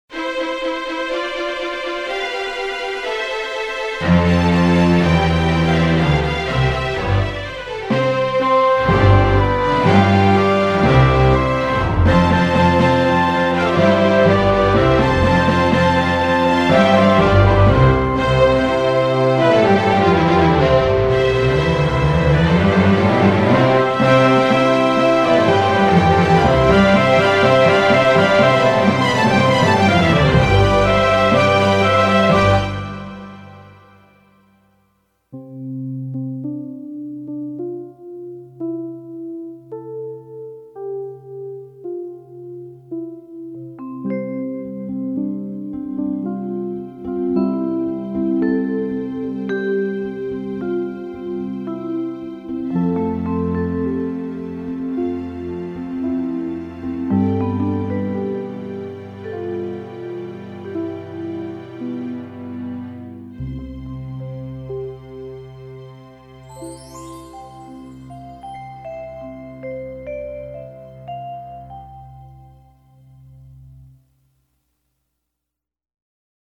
Классическая музыка величайшего композитора для взрослых и детей.
symphony-no.41-in-c.mp3